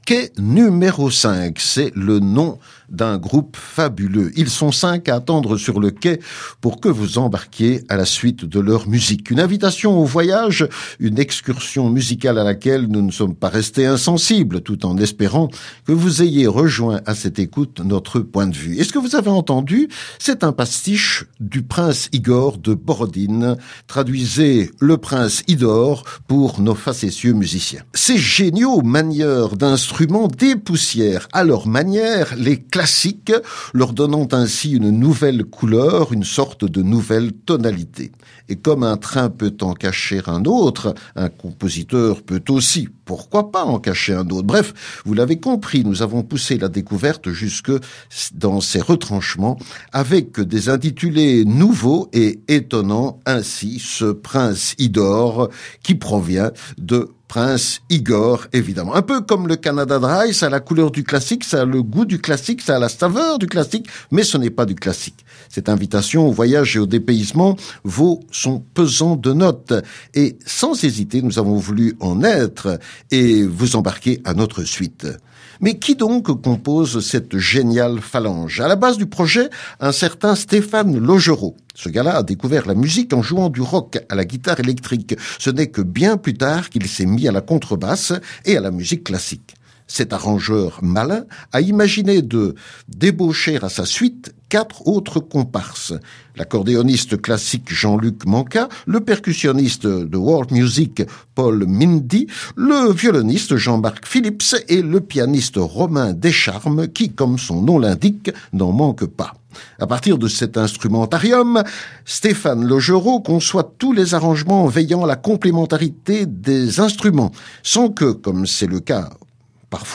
Une invitation au voyage, avec 5 musiciens à bord !